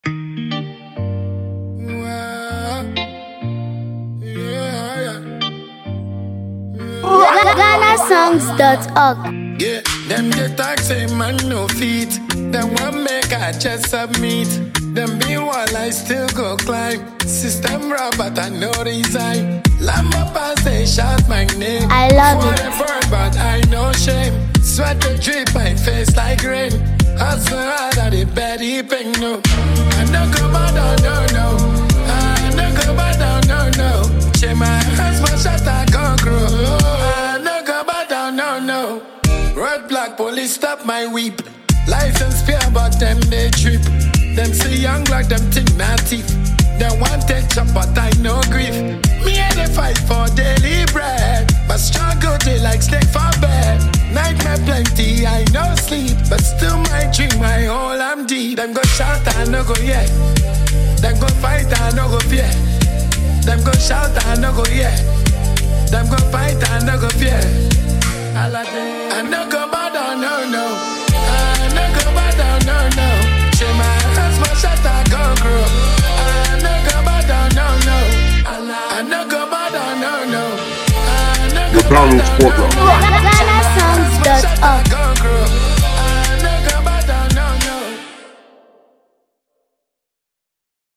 Dancehall
With sharp lyrics and a fearless delivery